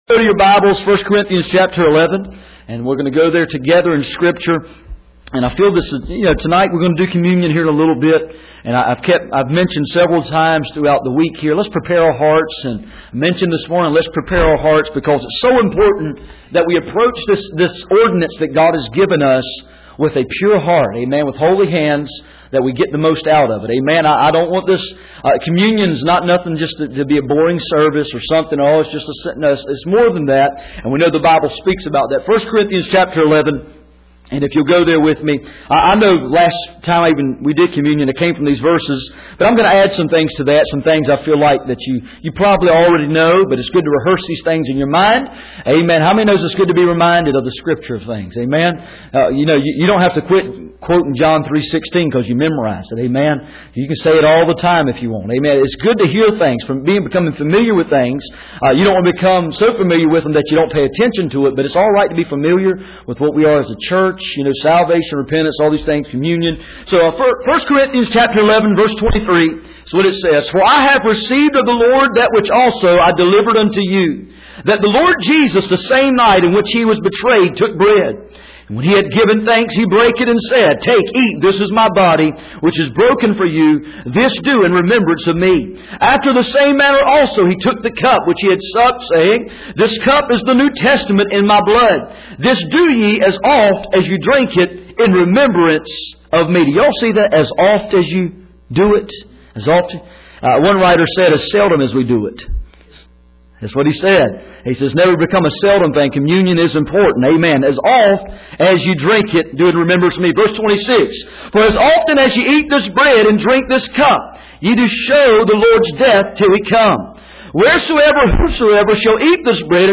Passage: 1 Corinthians 11:23-26 Service Type: Sunday Evening